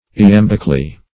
Meaning of iambically. iambically synonyms, pronunciation, spelling and more from Free Dictionary.
iambically - definition of iambically - synonyms, pronunciation, spelling from Free Dictionary Search Result for " iambically" : The Collaborative International Dictionary of English v.0.48: Iambically \I*am"bic*al*ly\, adv.